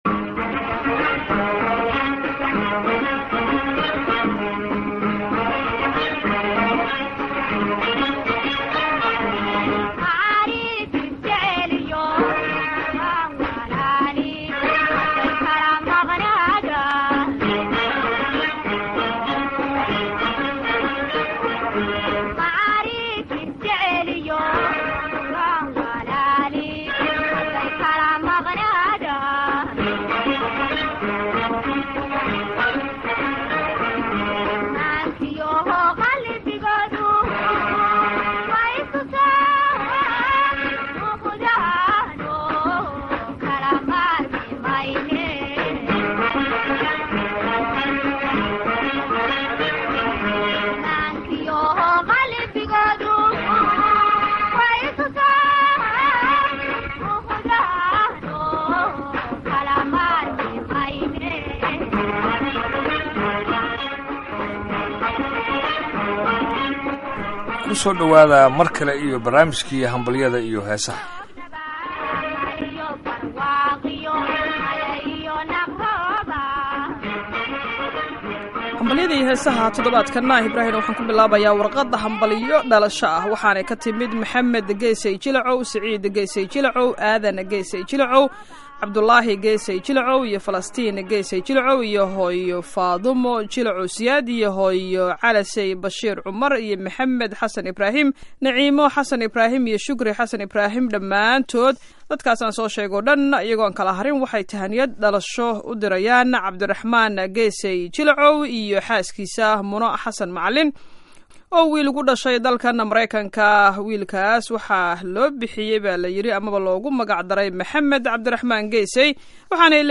Waa Barnaamijkii Hambalyada iyo Heesaha.